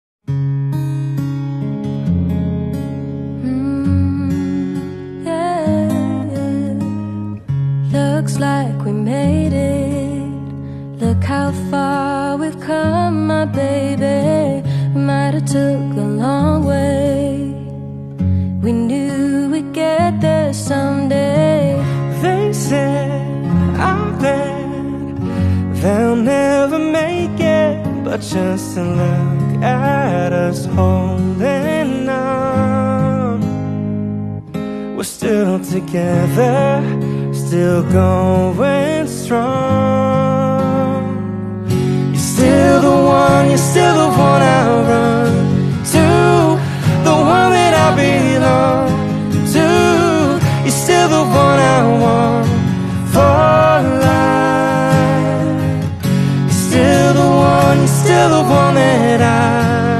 acoustic cover